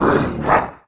Sound / sound / monster / yellow_tigerman / attack_2.wav
attack_2.wav